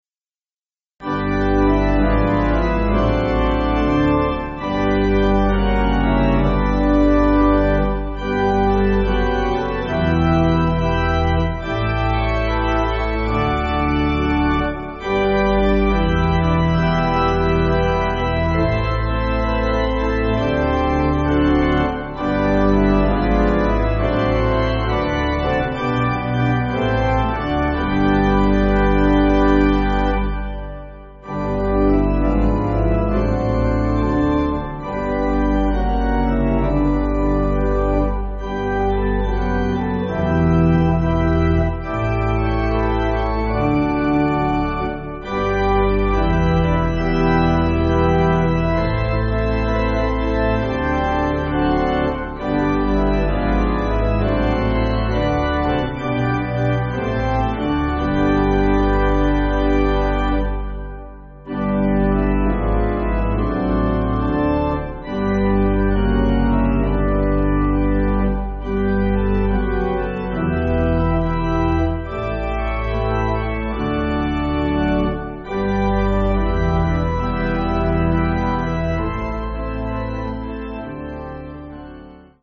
Organ
(CM)   6/G